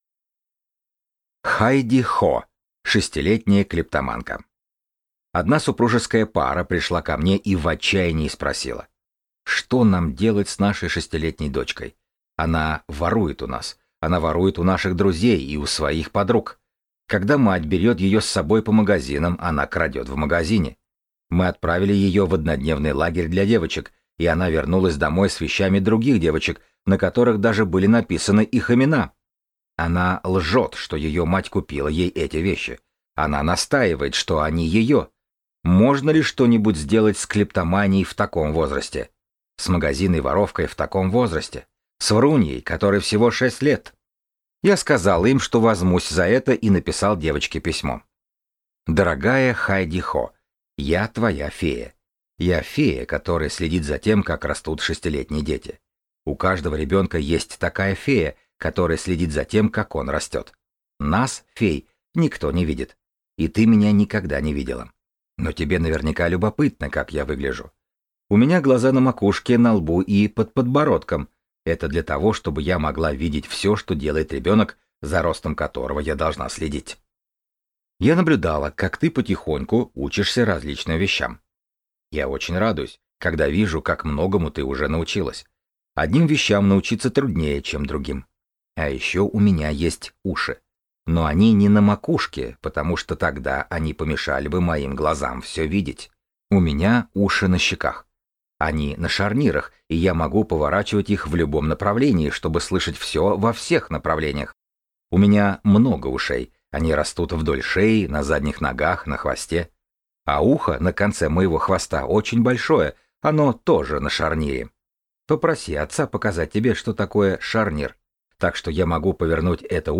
Аудиокнига Обучение ценностям и самодисциплине | Библиотека аудиокниг